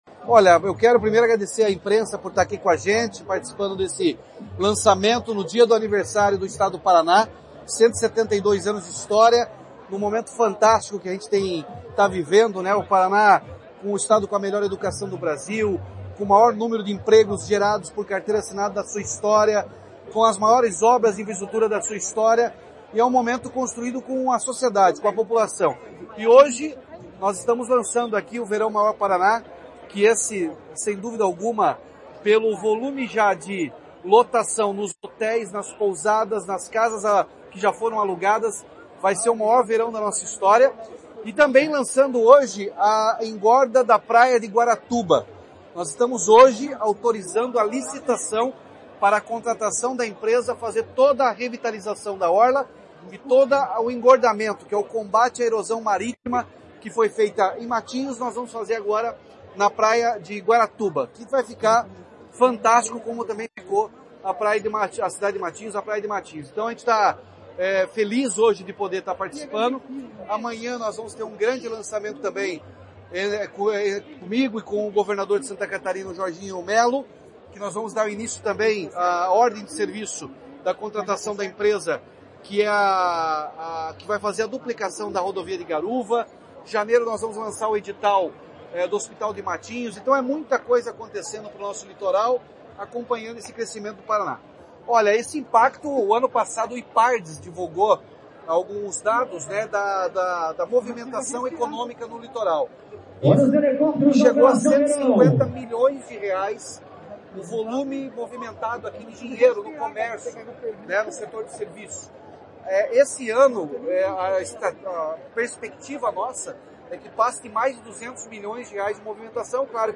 Sonora do governador Ratinho Junior sobre o lançamento do Verão Maior Paraná